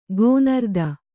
♪ gōnarda